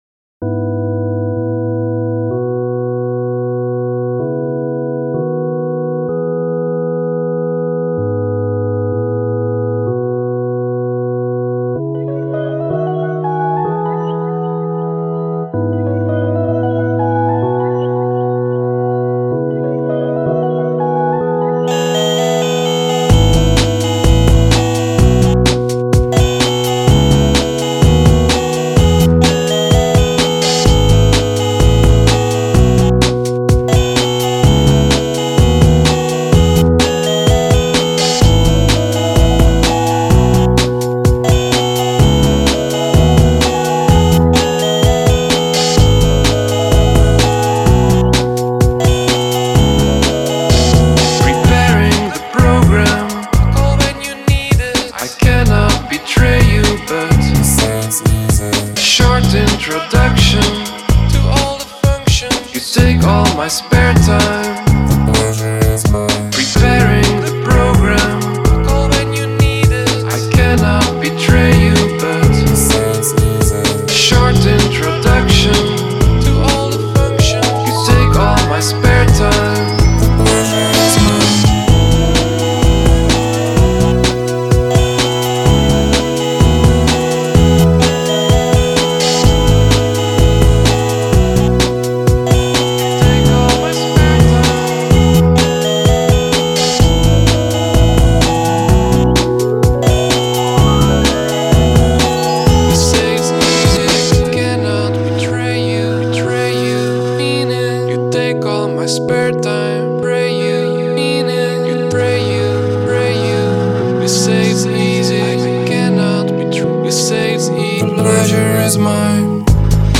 chiptune/NES influences